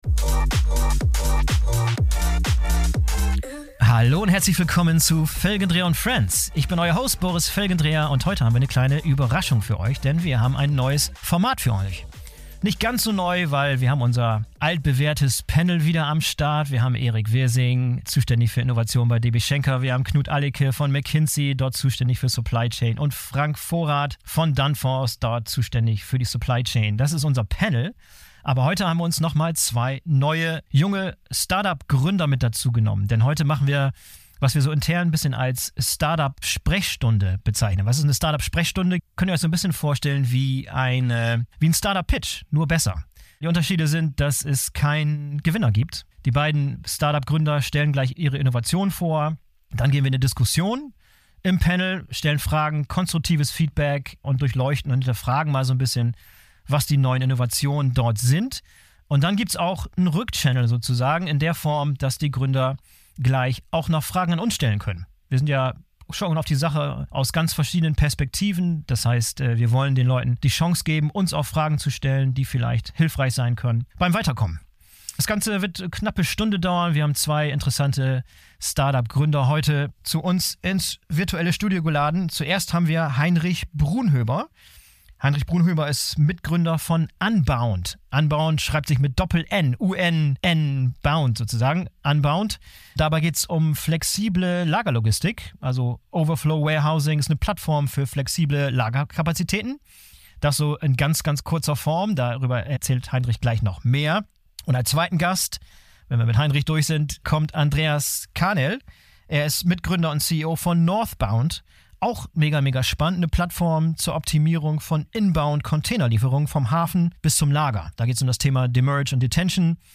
Heute präsentieren uns zwei Gründer ihre Startup-Ideen. Dann diskutieren wir diese Ideen in unserer Runde von Supply Chain und Logistik Experten und geben den Gründern hilfreiches Feedback.